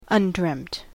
詳細はFile:En-us-undreamt.oggのライセンス要件を参照ください。